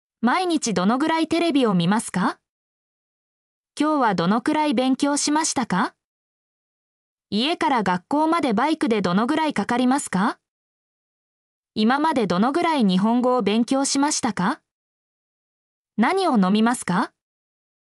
mp3-output-ttsfreedotcom-50_rlQwzJbF.mp3